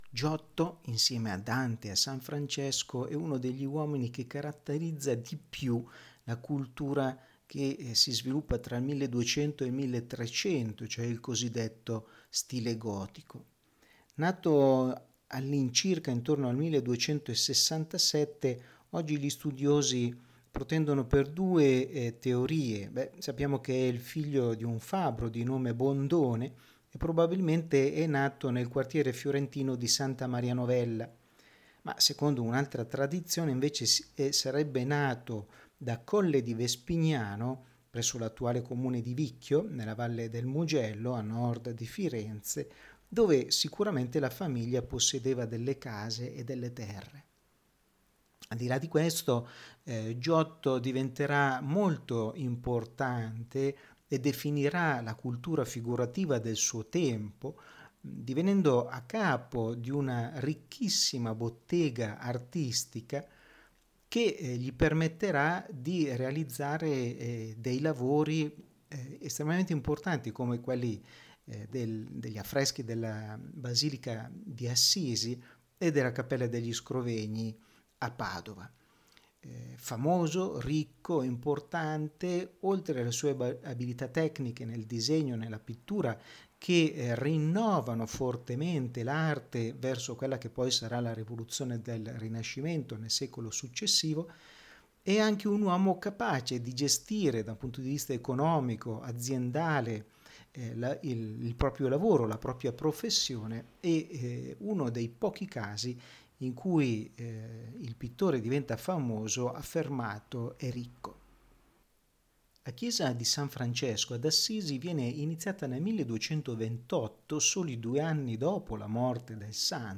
Ascolta la lezione audio dedicata a Giotto Giotto